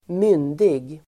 Ladda ner uttalet
myndig adjektiv, of age (majority)Uttal: [²m'yn:dig] Böjningar: myndigt, myndigaDefinition: (om person) som fyllt 18 årRelaterade ord: omyndig (antonym) (minor)